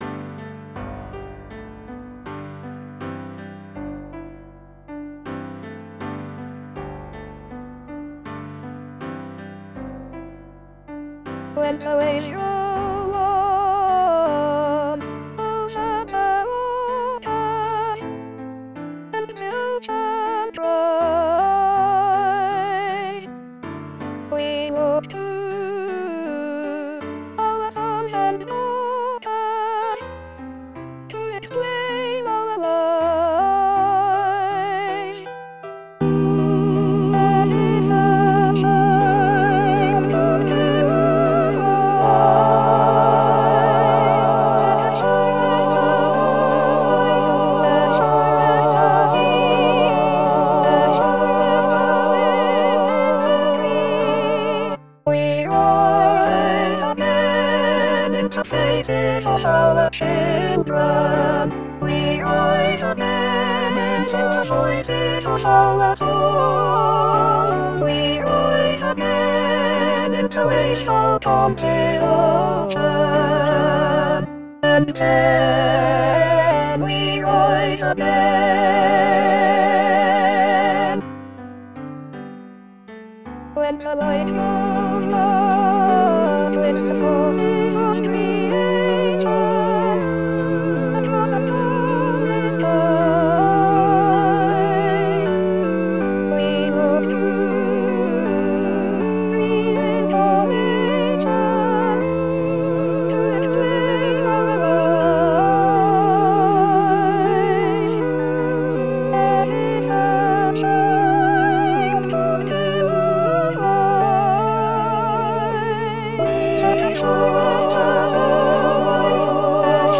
Full choir